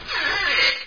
zvuk-skripa-dveri.ogg